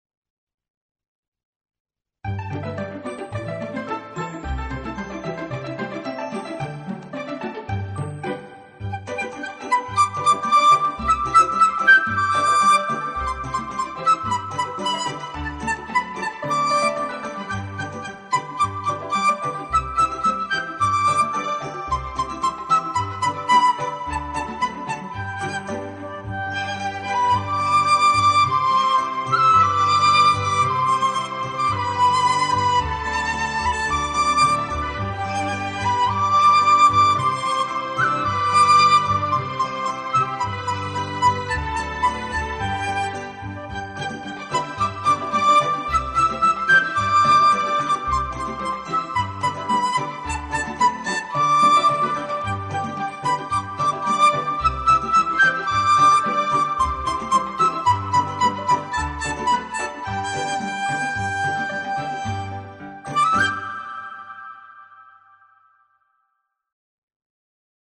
今年共有47个贴（有3个联奏），其中笛子曲47首、葫芦丝曲3首，合计50首乐曲。